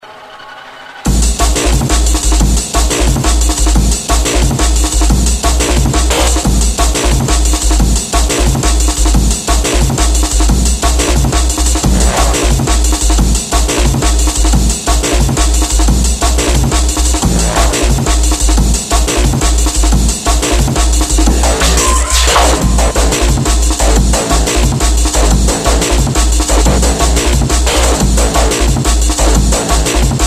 Drum'n'bass